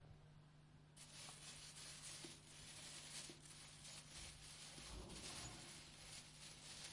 描述：纸沙沙作响